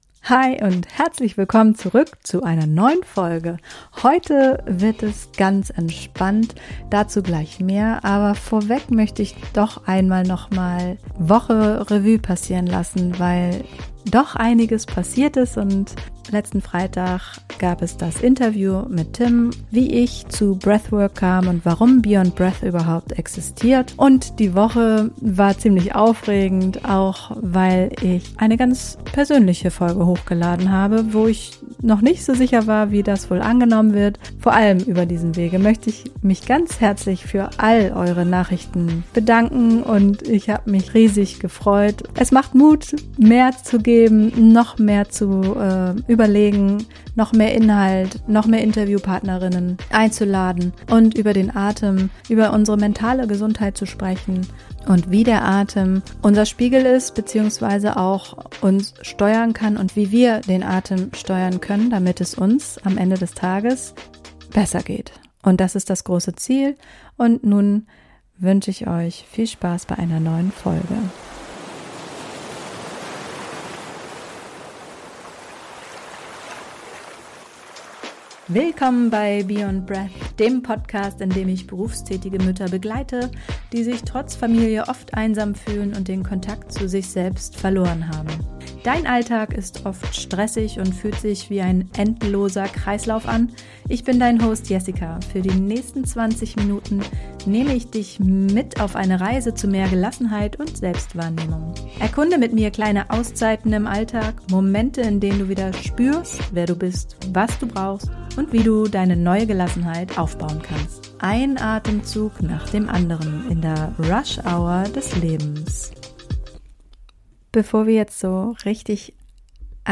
Beschreibung vor 11 Monaten In dieser Episode bekommst du eine 7-Minuten Breathwork-Oase, die dir zeigt, wie du mit wenigen bewussten Atemzügen sofort Ruhe, Fokus und einen langen Atem bekommst und zwar genau dann, wenn der Feiertags-Wirbel am lautesten ist. Drück auf Play, atme dich frei – und erlebe, wie wenig Zeit du brauchst, um Feiertagsstress in Feiertags-Flow zu verwandeln.